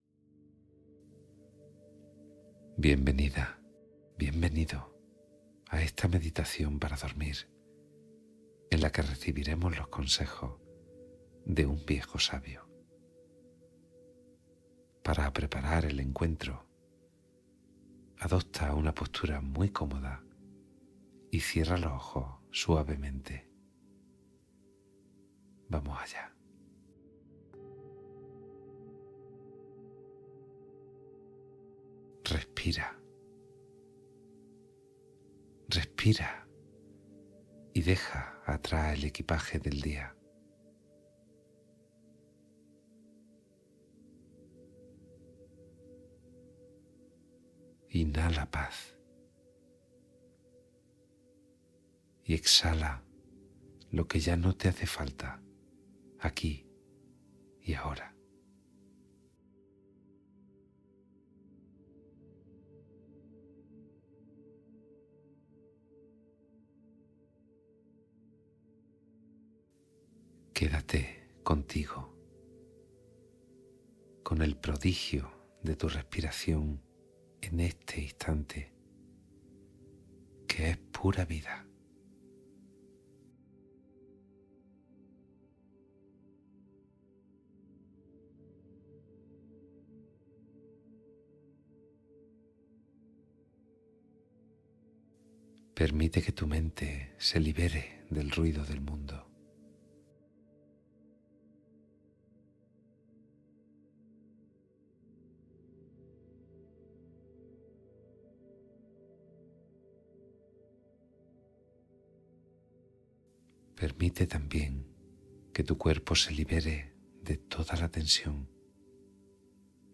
Meditación Vivencial con Reflexiones para Preparar el Descanso